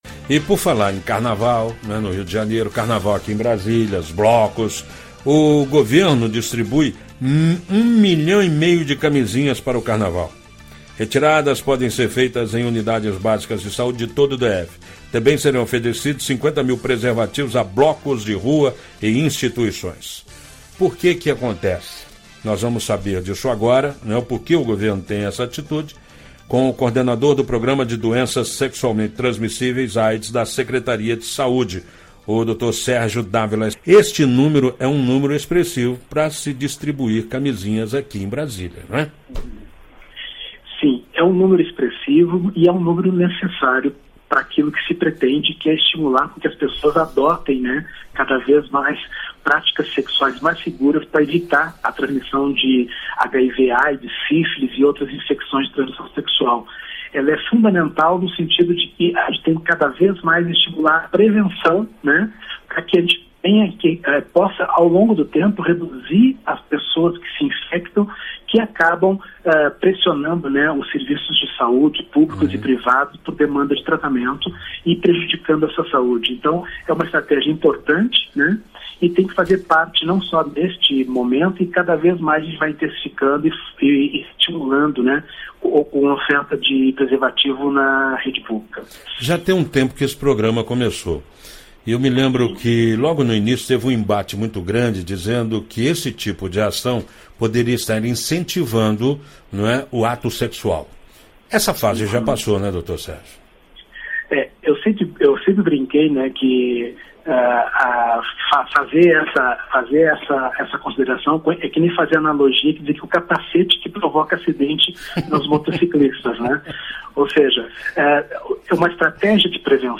Entrevista: Saiba como será a distribuição de 1,5 milhão de camisinhas no carnaval do DF